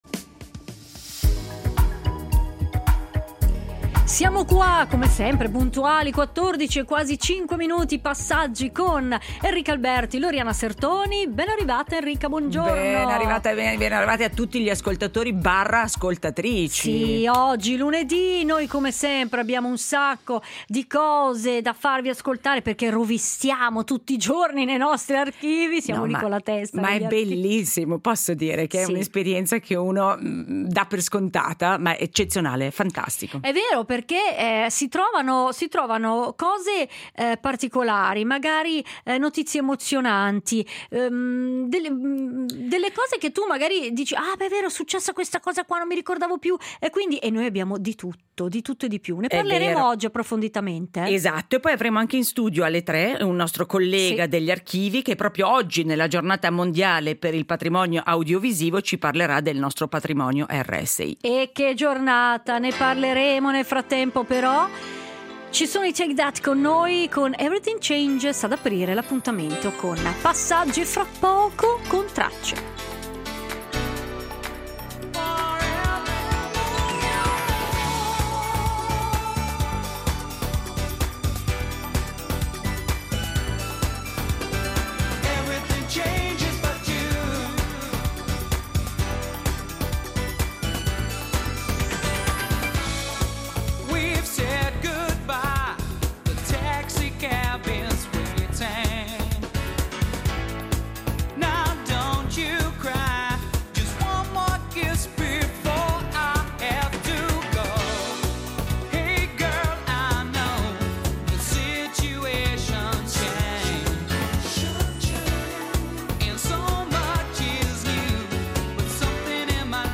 In Tracce estratti d’archivio sull’inaugurazione della diga della Verzasca, sull’apertura della Biblioteca Salita dei Frati e su Sandra e Raimondo , personaggi storici della televisione italiana.